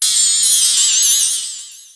本站所有音效均采用 CC0 授权，可免费用于商业与个人项目，无需署名。